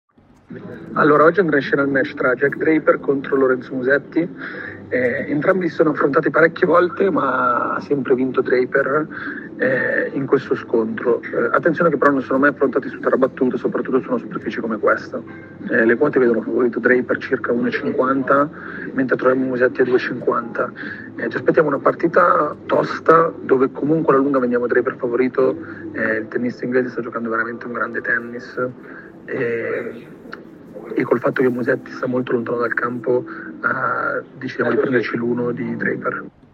audio analisi